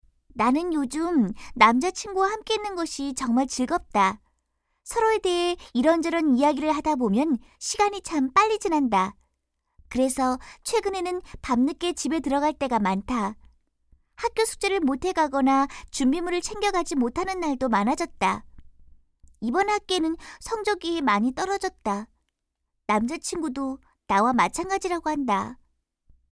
120쪽-내레이션.mp3